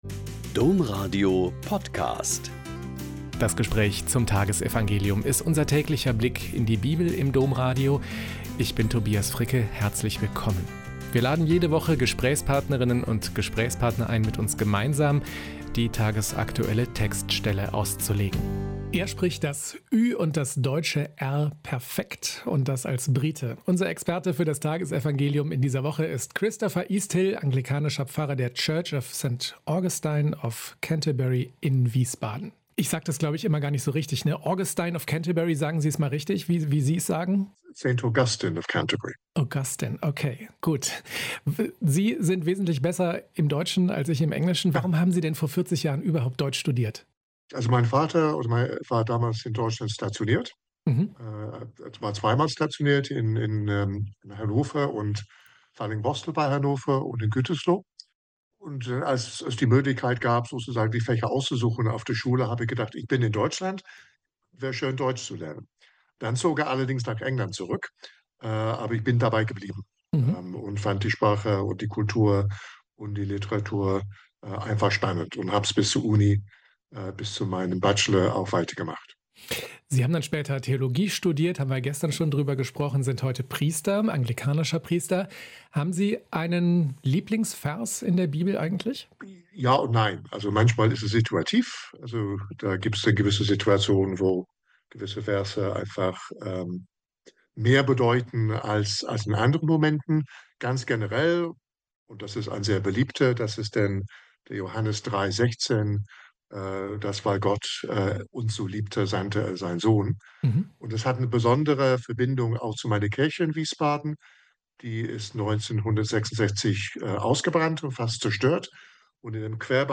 Mt 13,10-17 - Gespräch